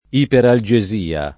iperalgesia [ iperal J e @& a ] s. f. (med.)